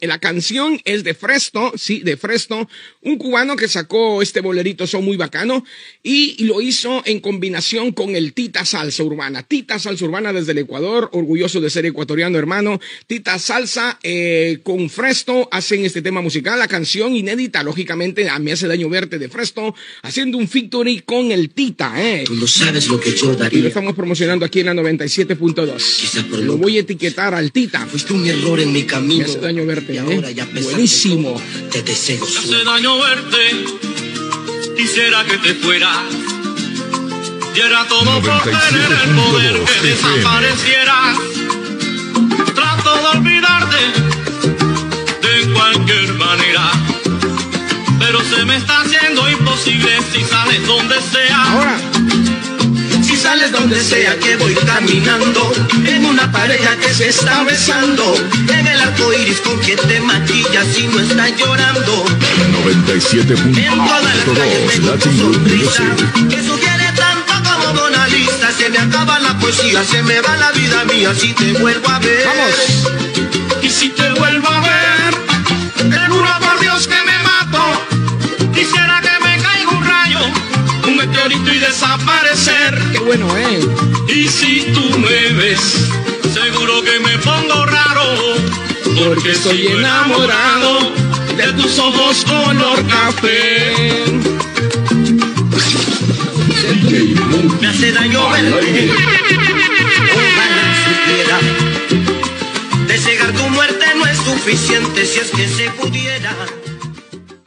Promoció d'un tema musical amb indicatius de l'emissora